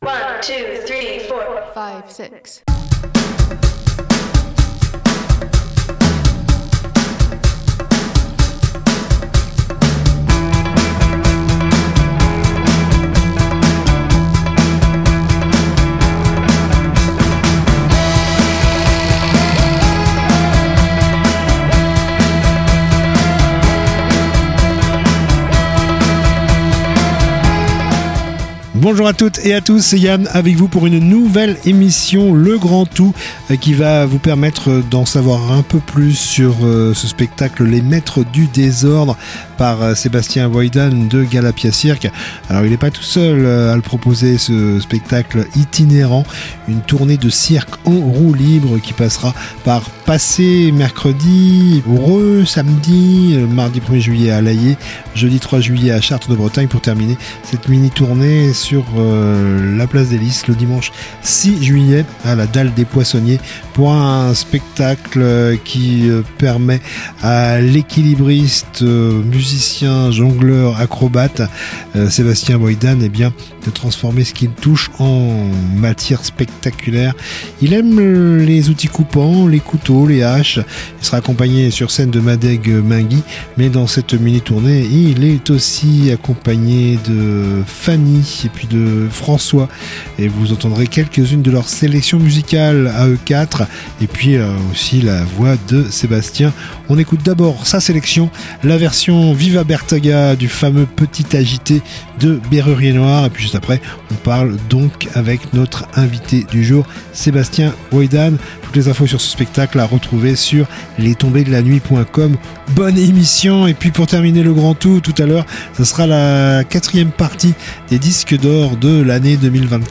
culture Discussion